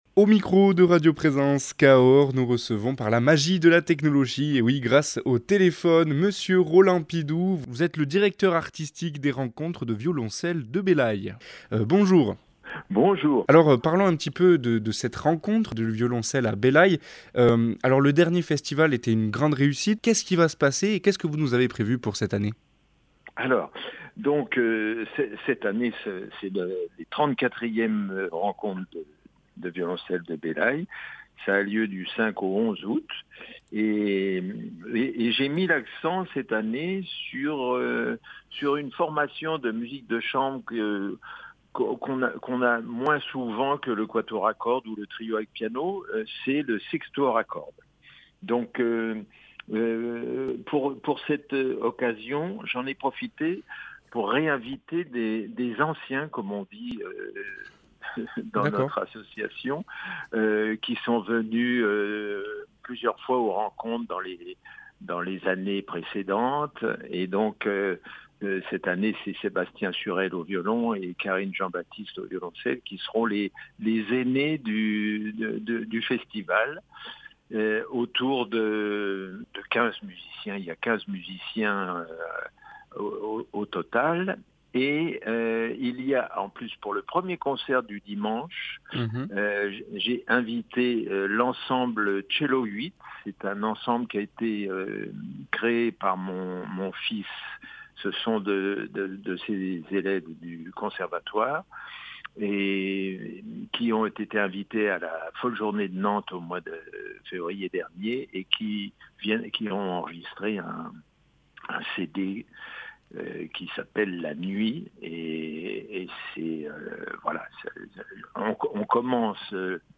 Nous avons reçu au micro de radio présence par téléphone Mr Roland Pidoux directeur artistique des Rencontres de violoncelles de Belaye qui est venu nous parler de l’édition 2023 des rencontres de violoncelles de Belaye